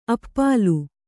♪ appālu